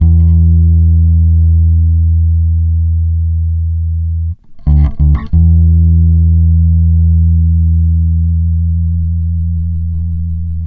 BALLAD90 E.wav